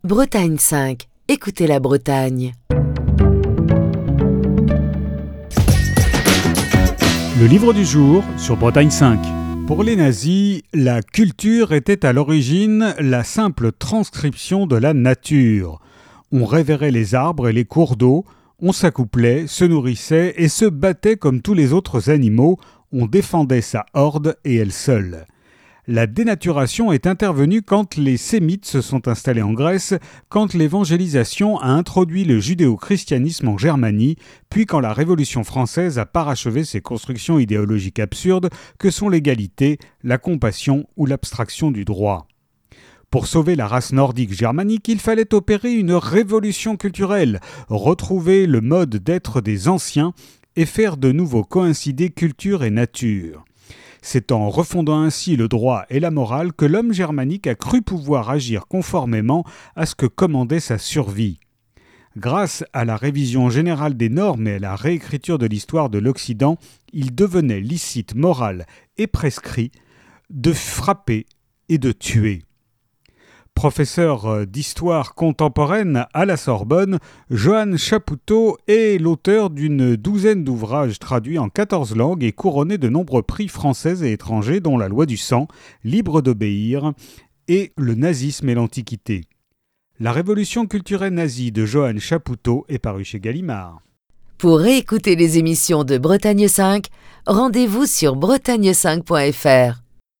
Chronique du 16 novembre 2022.